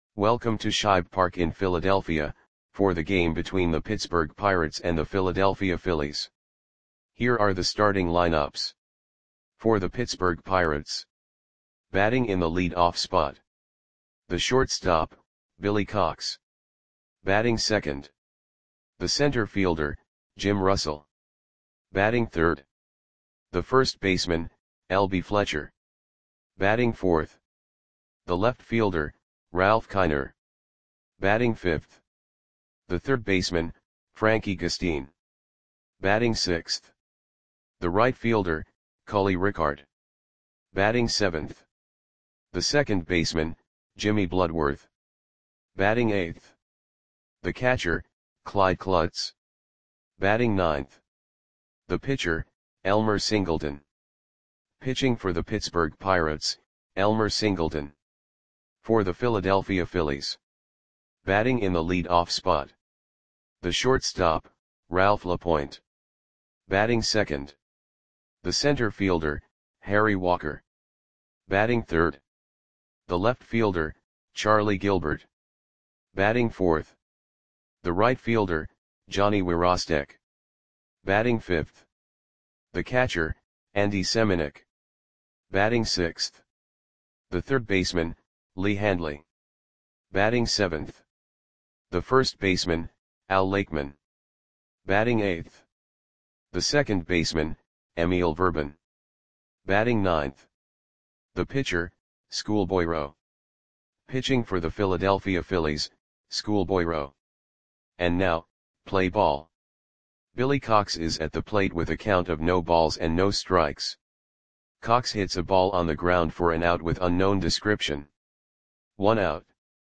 Audio Play-by-Play for Philadelphia Phillies on August 28, 1947
Click the button below to listen to the audio play-by-play.